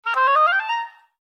17_Clarinet.ogg